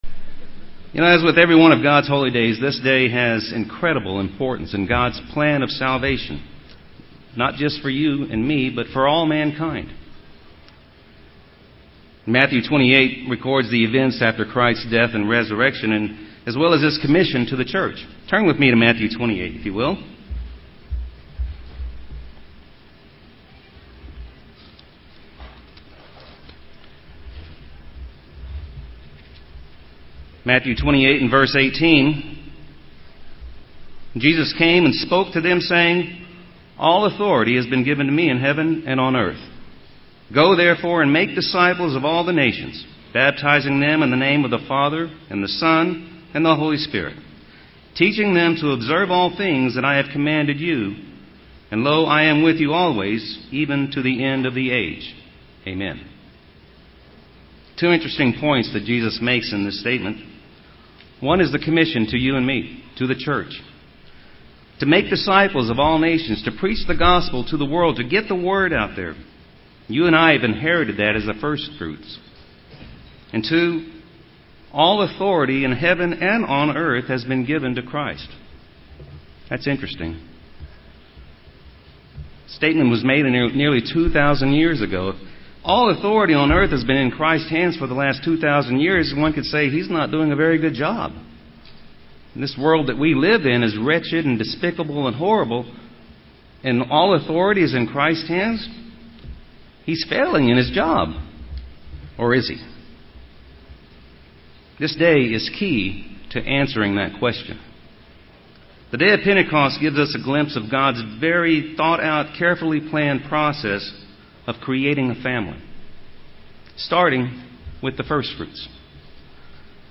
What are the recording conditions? Given in San Antonio, TX